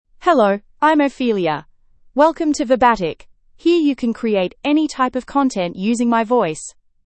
Ophelia — Female English (Australia) AI Voice | TTS, Voice Cloning & Video | Verbatik AI
Ophelia is a female AI voice for English (Australia).
Voice sample
Listen to Ophelia's female English voice.
Ophelia delivers clear pronunciation with authentic Australia English intonation, making your content sound professionally produced.